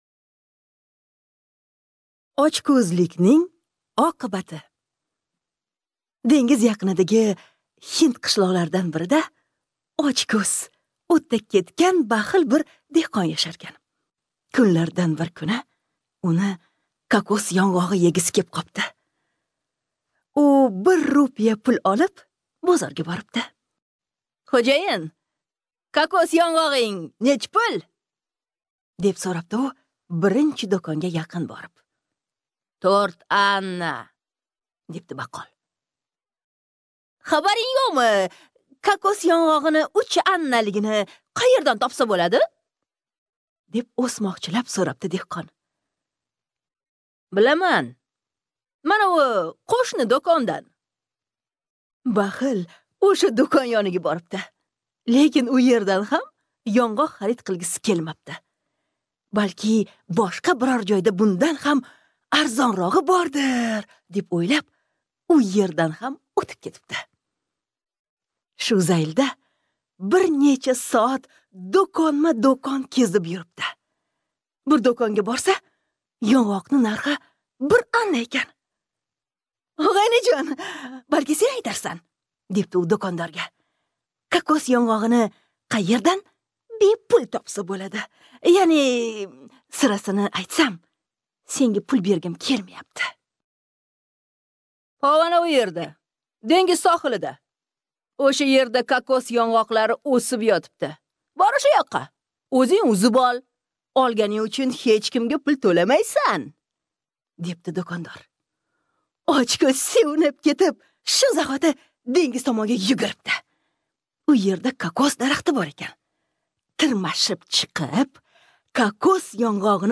Очкӯзликнинг оқибати (АУДИОЭРТАК)